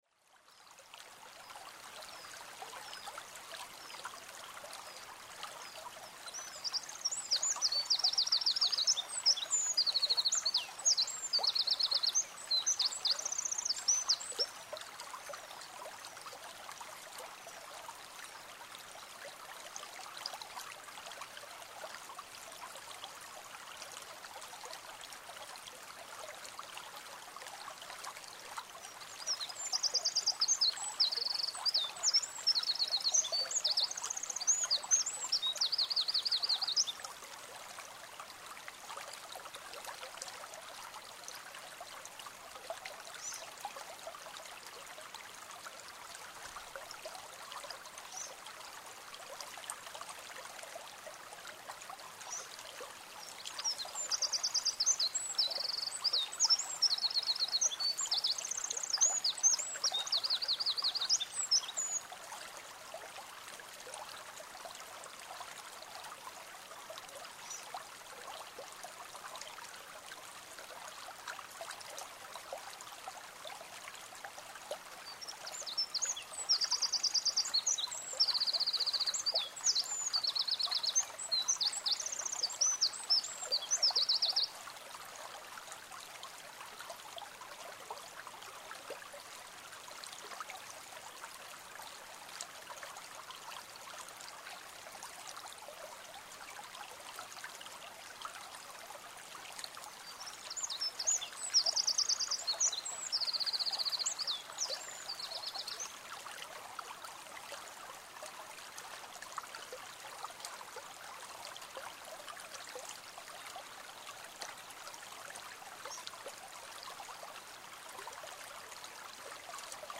Það ríkti því dásamleg þögn.  Aðeins heyrðist seytlið í læknum og einstaka tíst í fuglum. Tekið var upp á Korg MR1000 í 24 bit/198 kHz. Hljóðnemar voru tveir Sennheiser  ME62 í 80° horni.
vic3b0-lc3a6k-i-vesturdal.mp3